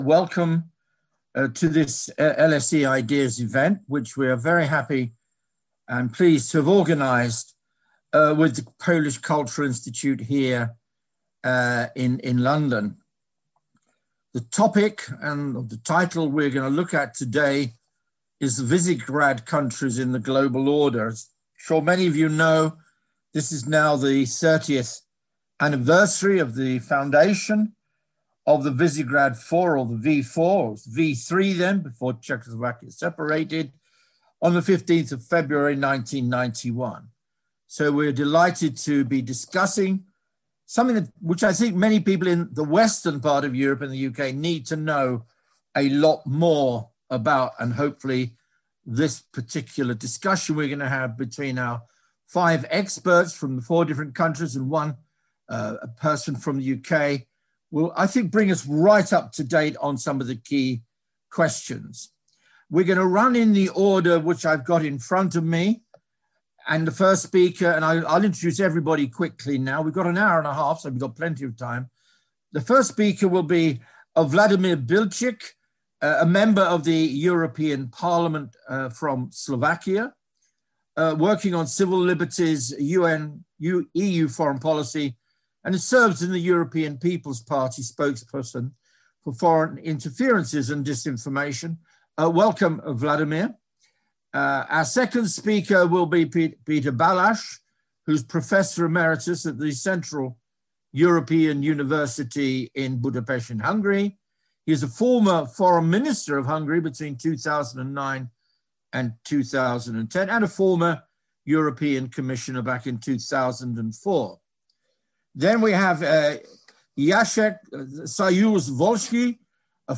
Catch up on our event discussing the role of the Visegrad Group in the global order.